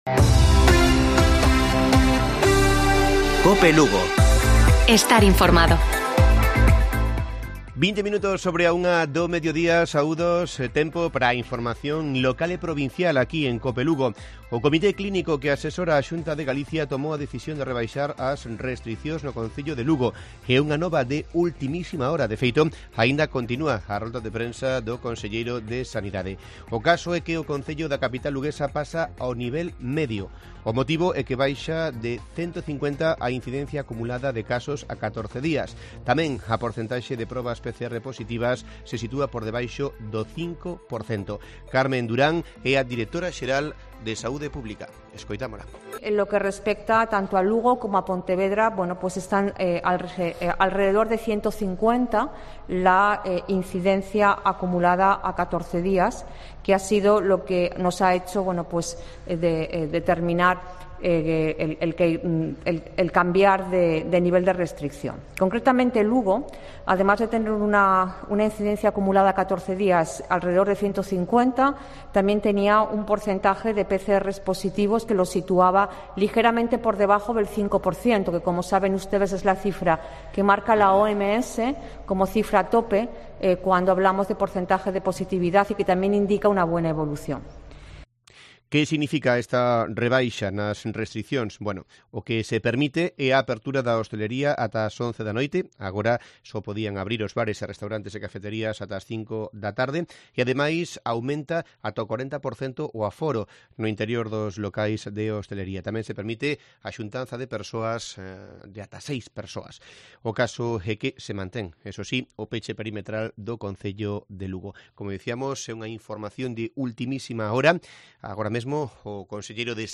Informativo Provincial de Cope lugo. 11 de diciembre. 13:20 horas